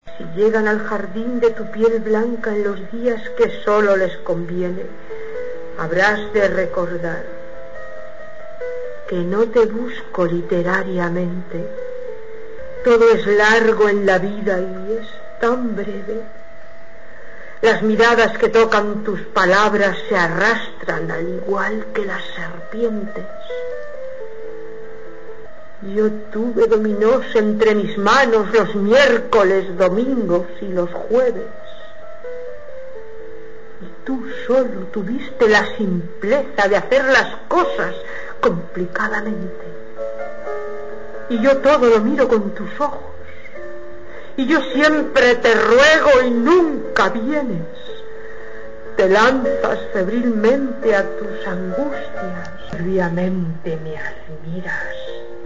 recitado.mp3